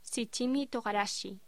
Locución: Shichimi Togarashi